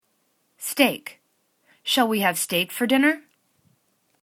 steak    /sta:k/     n